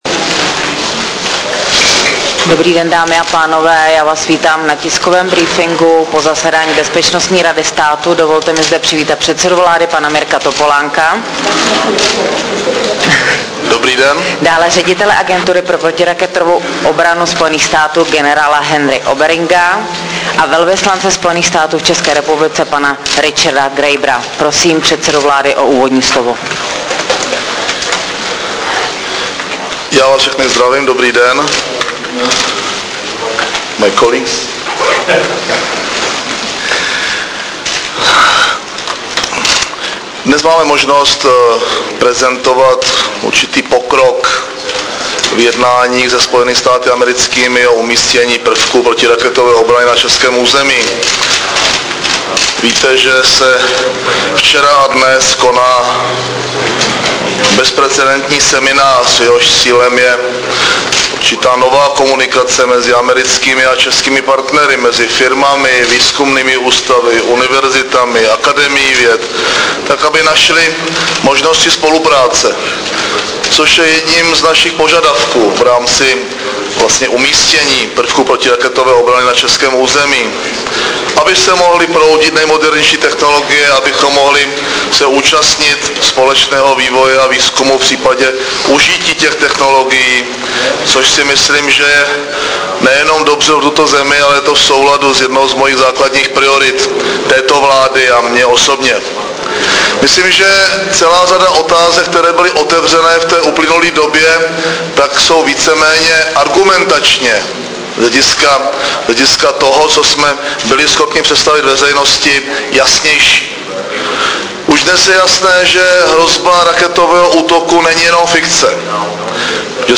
Tiskový briefing po zasedání Bezpečnostní rady státu 17. ledna 2008